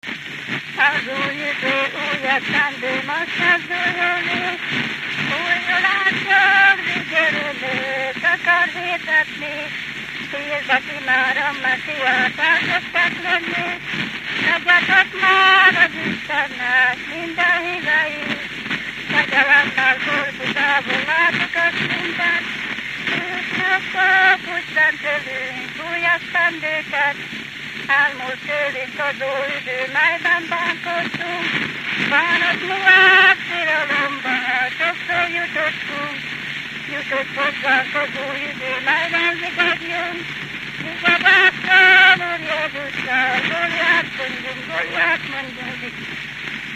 Dunántúl - Veszprém vm. - Dudar
ének
Műfaj: Újévi köszöntő
Stílus: 7. Régies kisambitusú dallamok